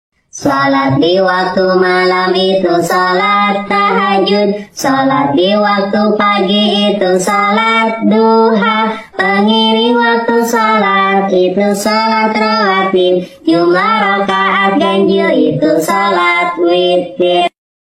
Lagu anak islami ~ macam sound effects free download